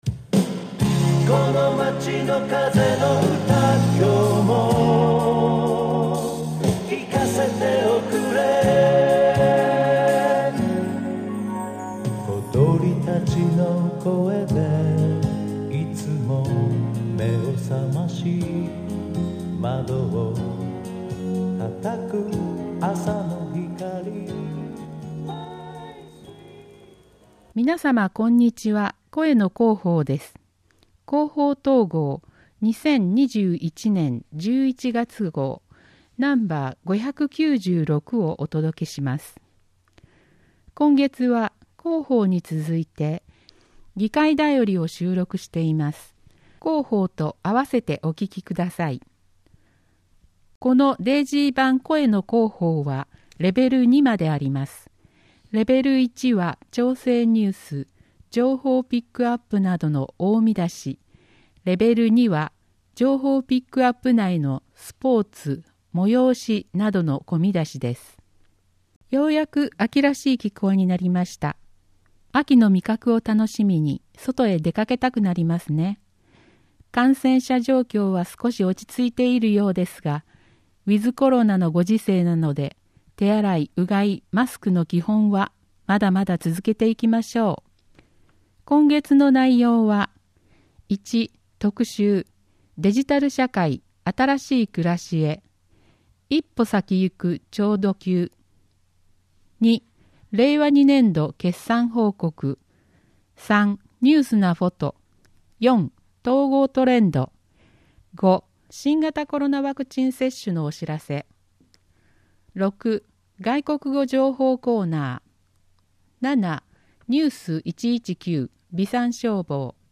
広報とうごう音訳版（2021年11月号）